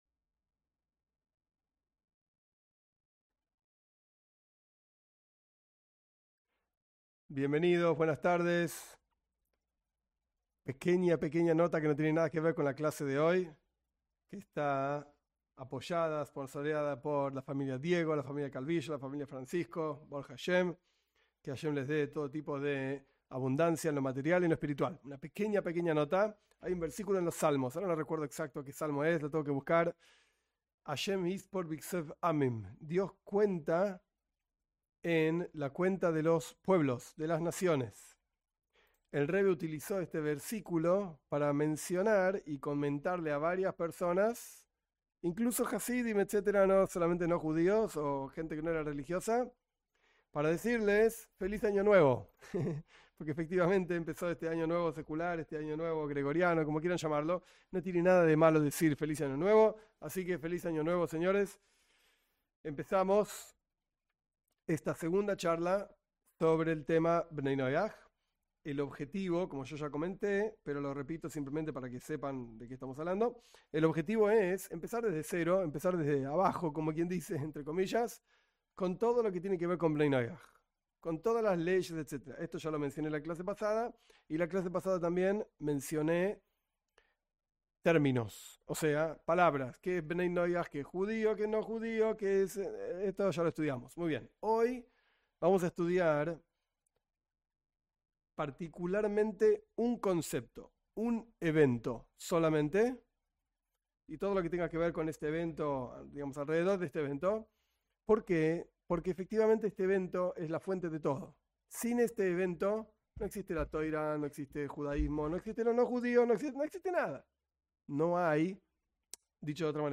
Cada clase tiene un tiempo de preguntas que los participantes preparan de antemano.